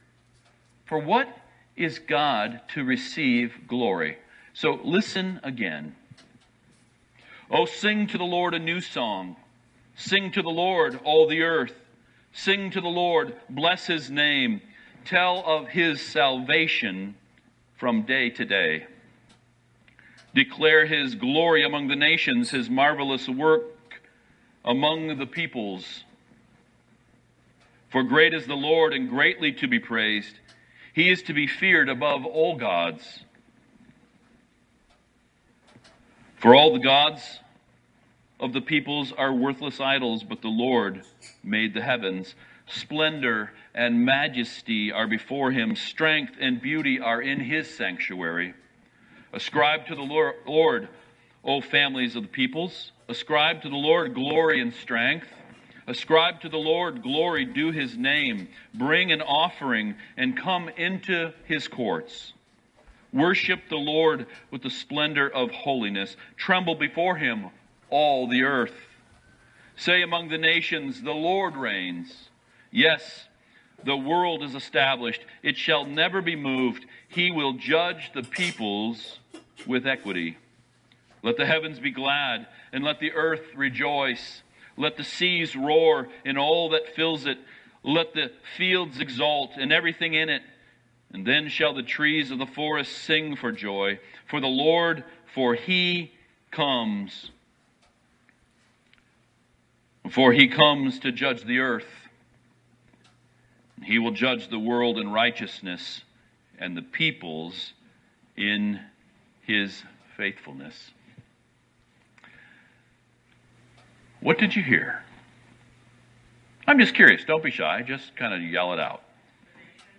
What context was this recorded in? Passage: Psalm 96 Service Type: Sunday Morning